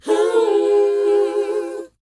WHOA D#BD.wav